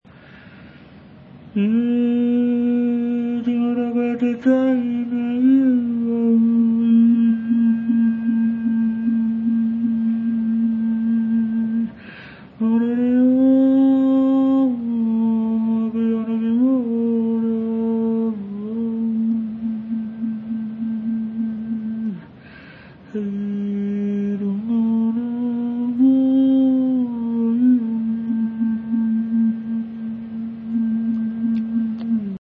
Nga Taonga Puoro Traditional Maori musical instruments
Putorino — trumpet / flute
Download an example of the putorino being sung into: